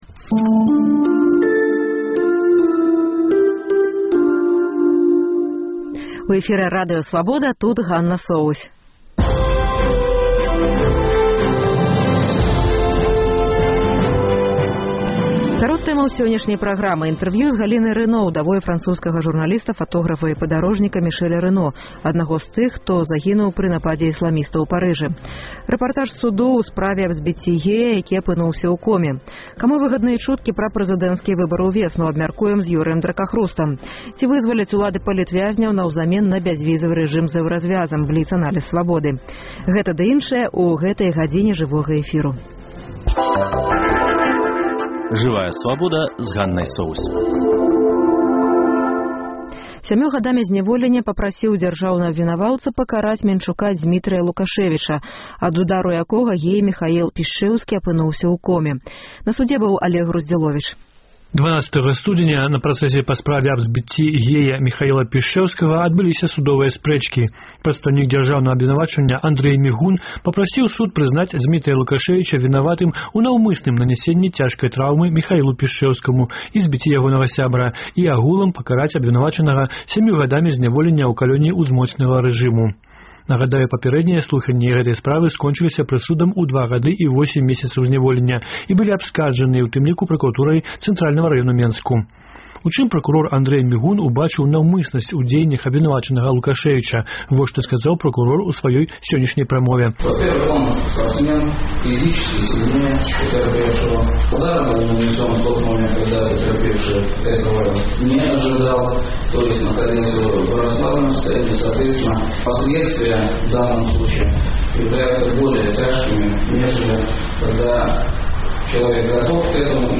Інтэрвію